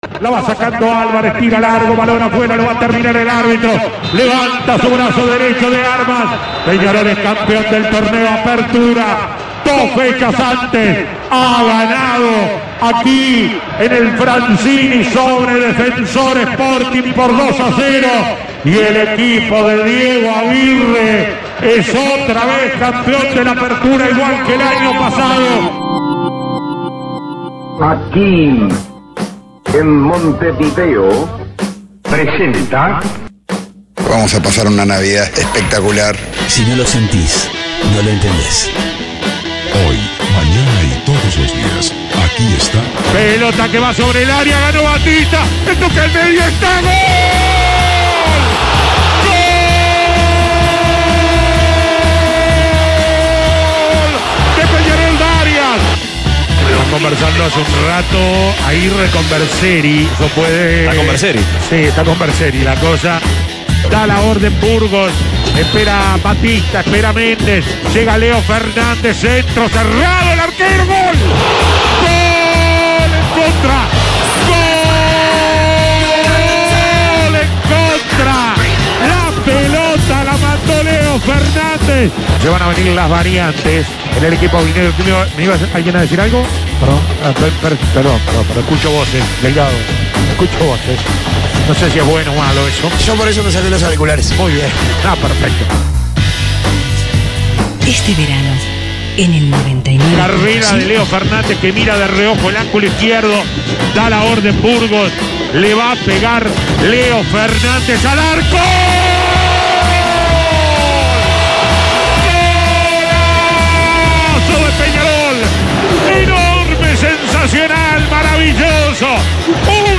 Periodístico deportivo